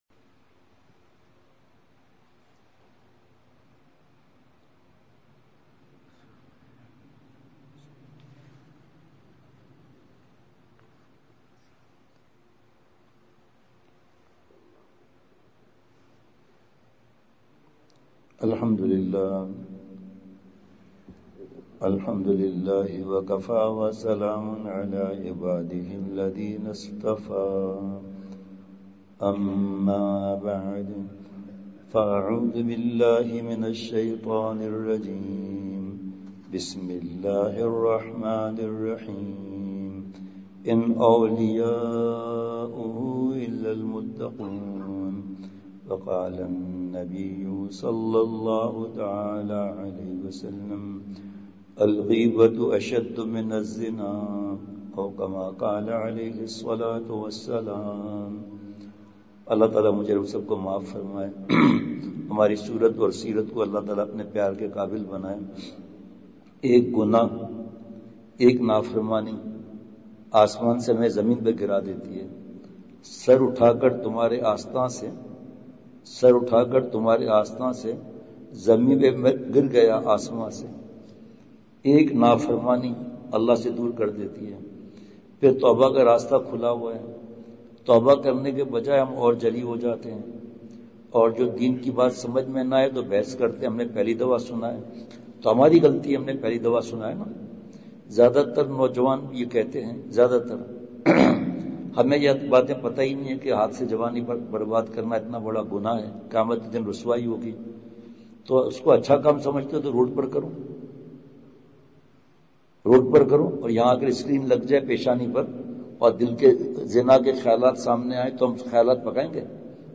سفر پنجاب بیان مغرب ۲۷ / اکتوبر ۲۵ء:بدنظری کا گناہ بہت ہی سنگین ہے !